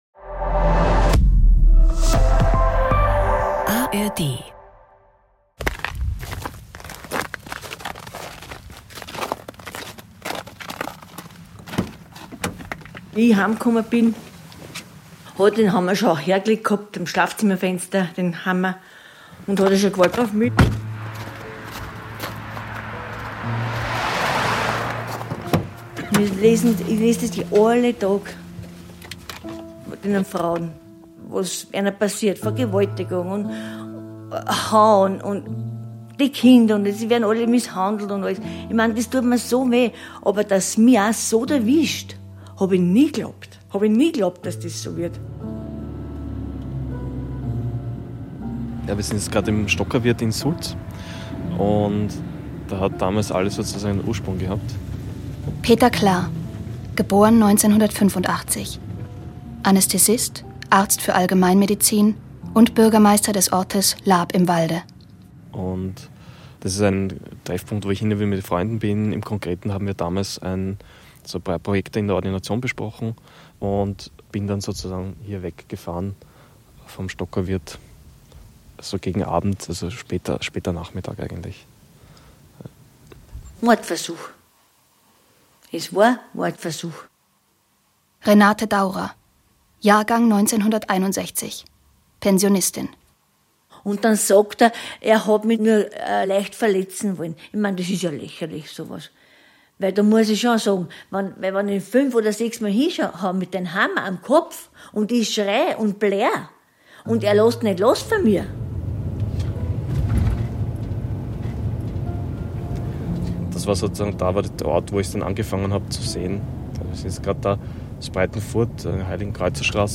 Dieses Feature wurde mit dem Juliane-Bartel-Medienpreis ausgezeichnet.